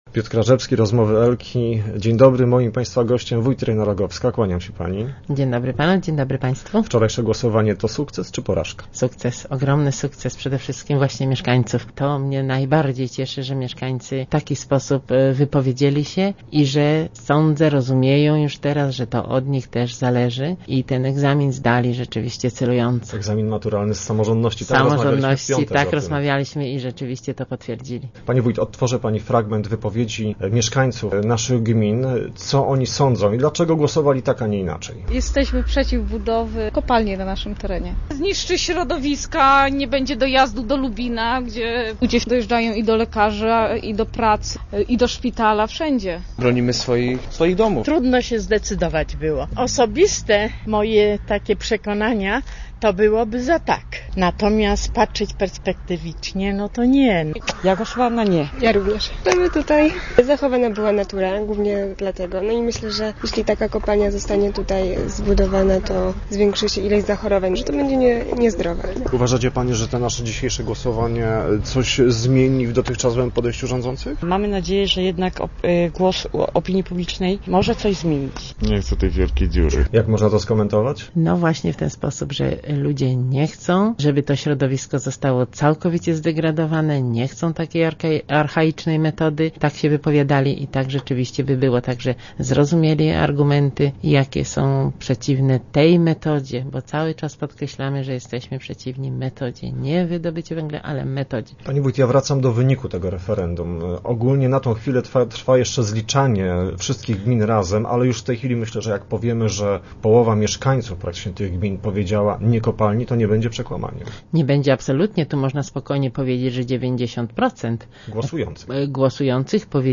Udział w referendum wzięła ponad połowa mieszkańców terenów zagrożonych kopalnią. O szczegółach rozmawialiśmy z wójt gminy Lubin, Ireną Rogowską.